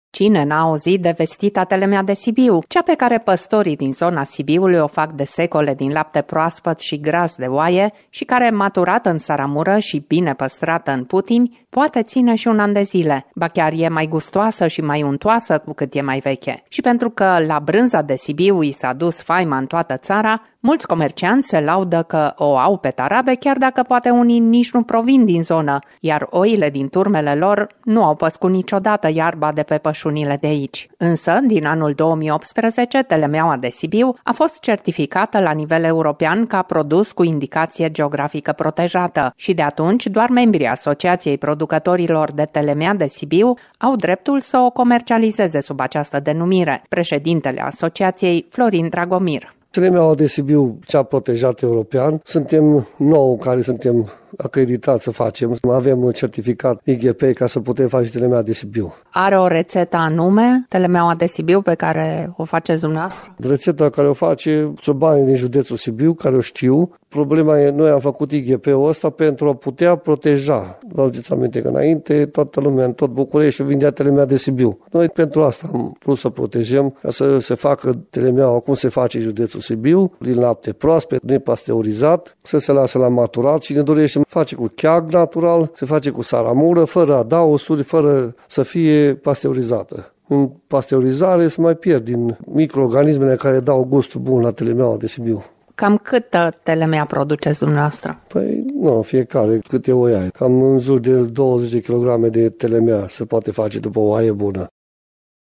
Prima pagină » Reportaje » Cui nu-i place Telemeaua de Sibiu?